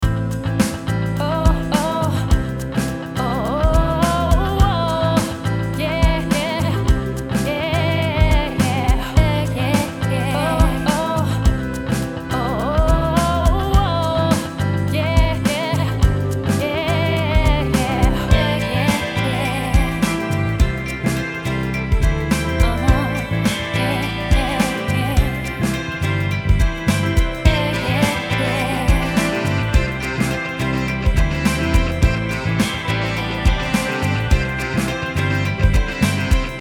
Каша в миксе (vocal /beat/ jingle)
Сегодня послушал в наушниках от айфона и показалось что барабаны и бас впереди планеты всей, да еще и толи "гундят" толи "бубнят". Так же, мне кажется, не хватает прозрачности и воздушности микса. Инструменты как-будто сливаются в одну кашу и превращаются в один сумбурный фон, особенно во второй части.